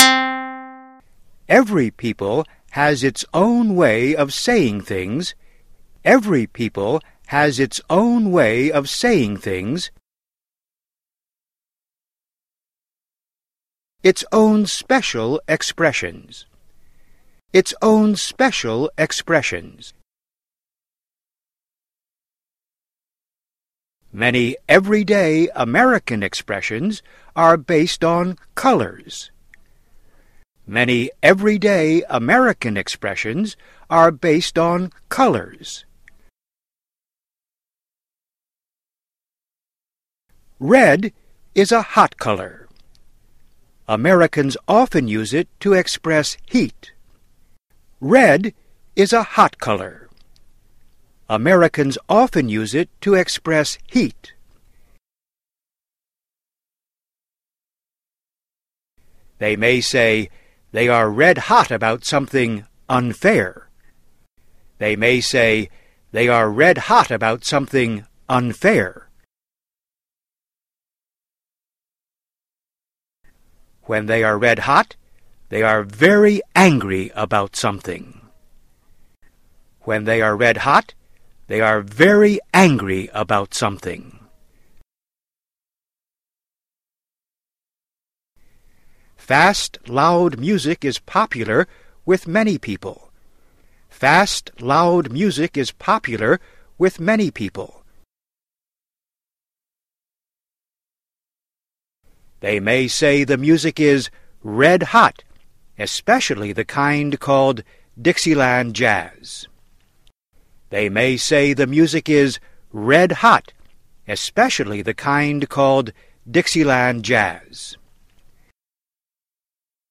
DICTATION / DICTADO
Cada frase se repite dejando un tiempo
dictation.mp3